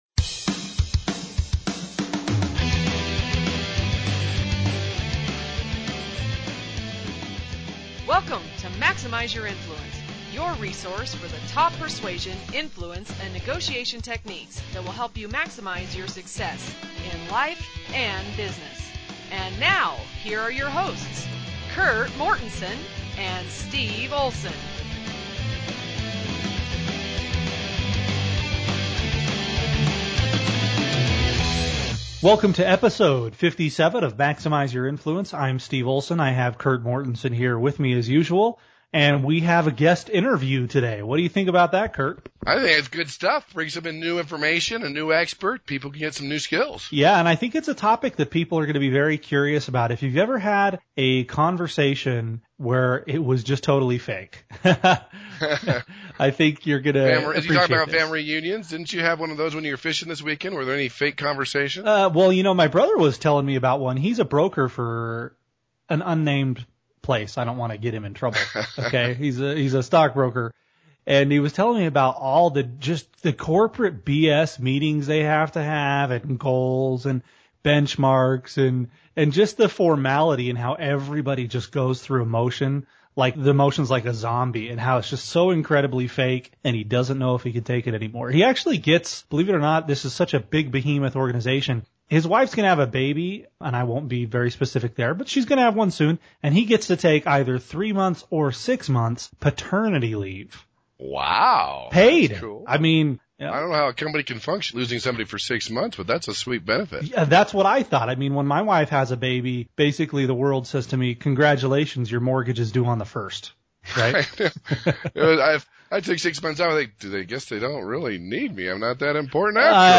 Episode 57 – “Overcoming Fake Talk” – Guest Interview